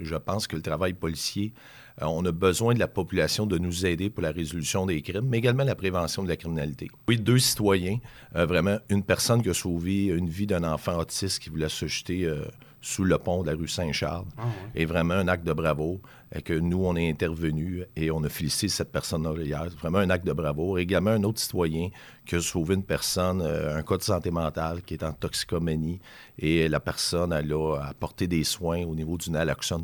Le directeur du SPG, Bruno Grondin, parle de deux citoyens honorés :